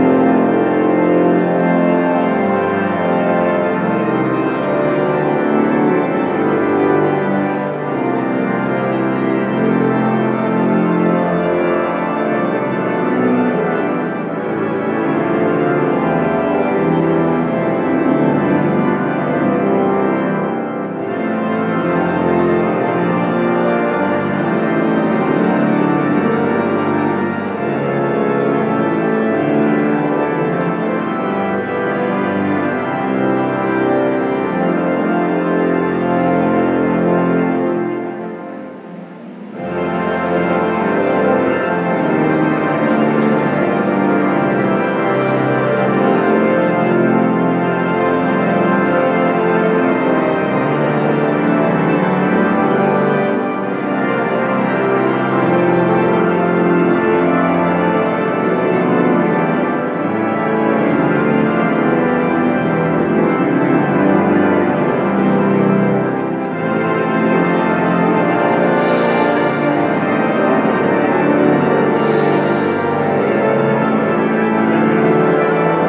Sounds from the Minster...